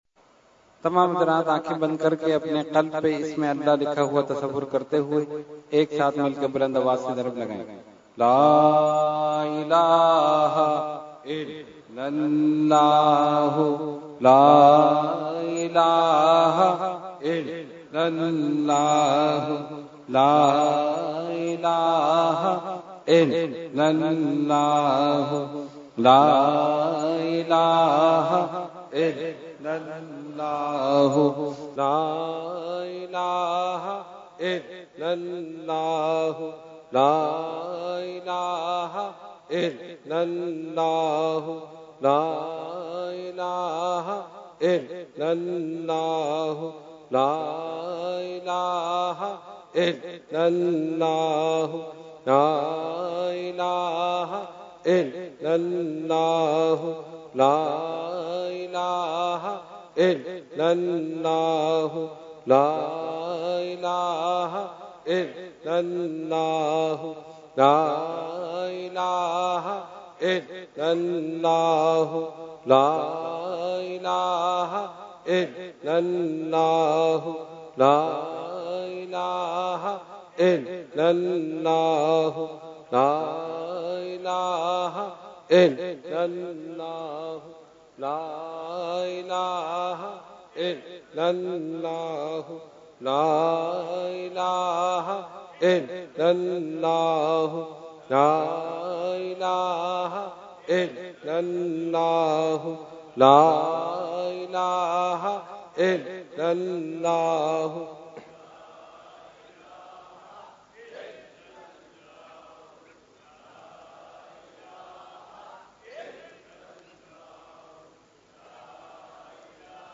Zikar wa Khusoosi Dua URS Qutbe Rabbani 2019 Day 3 – Dargah Alia Ashrafia Karachi Pakistan
02-Zikar Wa Khususi Dua.mp3